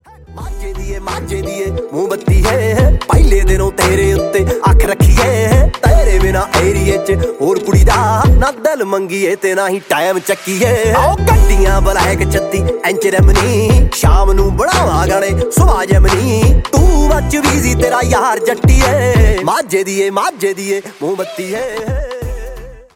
Punjabi song